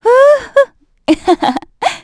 Valance-Vox_Happy4_kr.wav